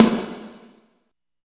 80 VERB SN-L.wav